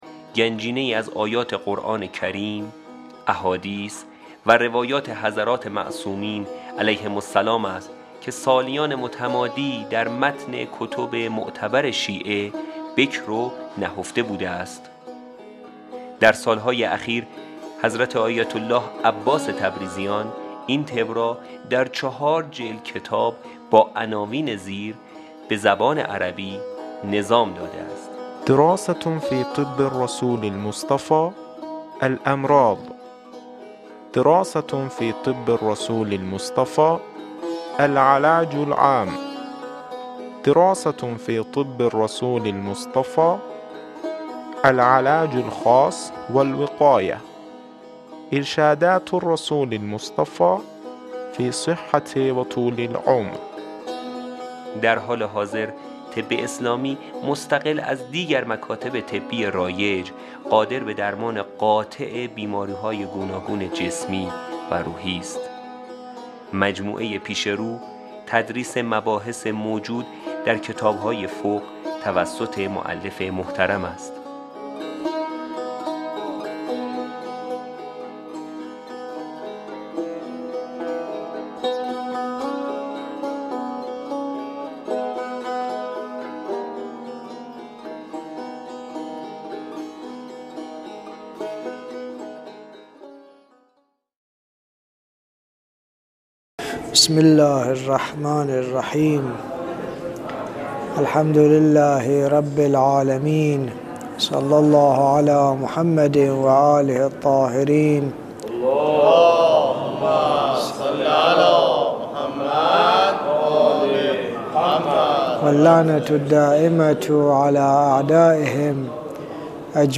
صوت تدریس کتاب الدراسة